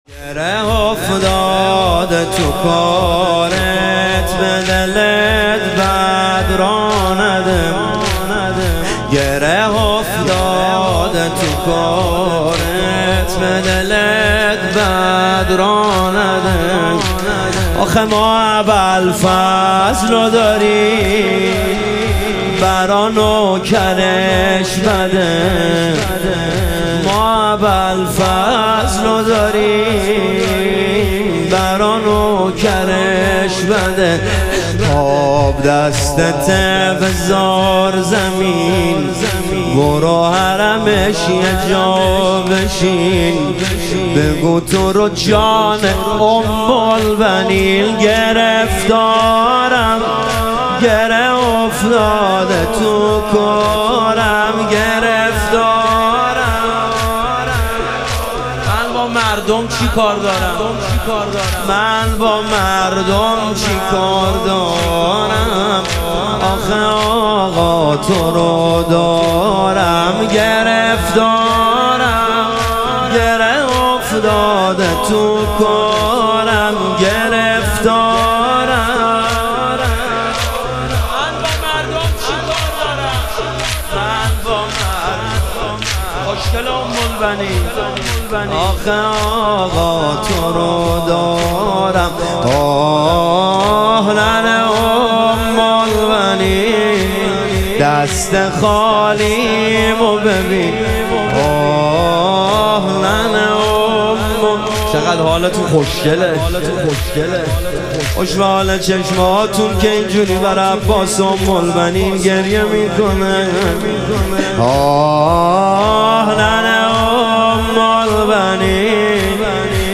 شهادت امام هادی علیه السلام - تک